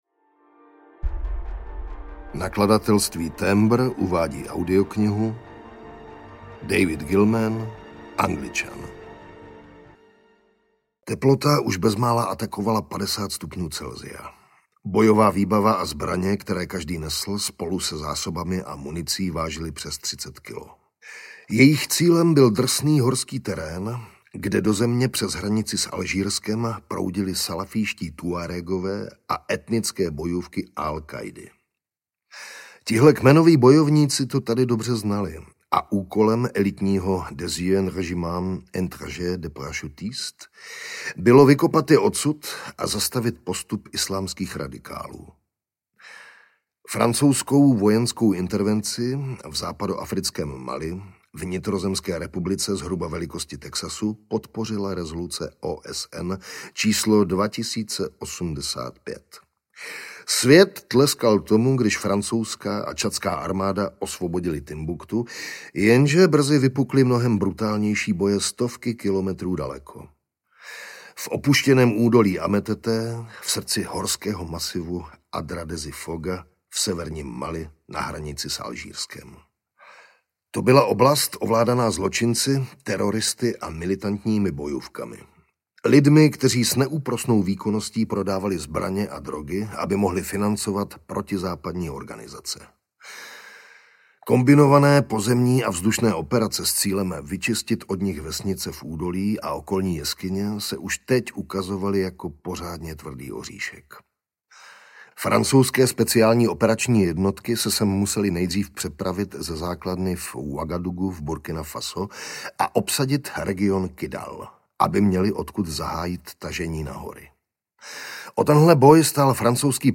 Angličan audiokniha
Ukázka z knihy
Čte Jiří Vyorálek
Hudba Karpof Brothers | Natočeno ve studiu KARPOFON (AudioStory)